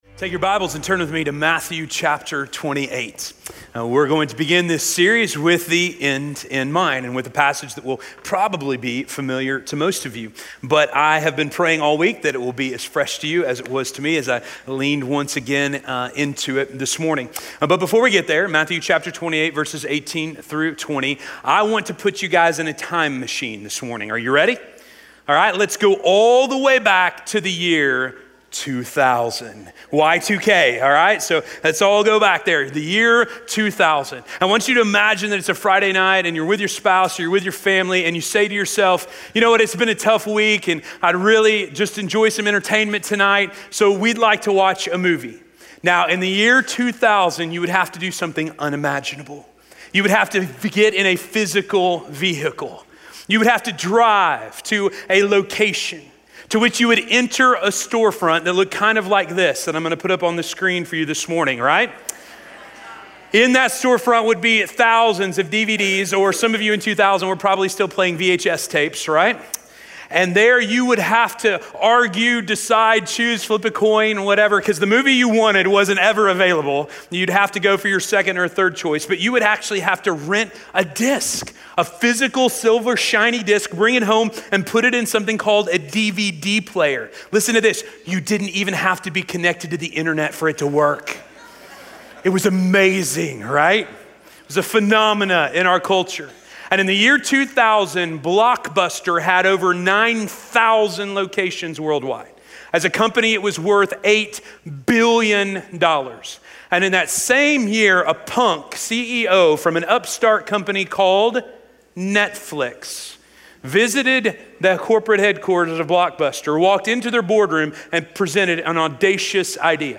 Disciples Making Disciples - Sermon - Station Hill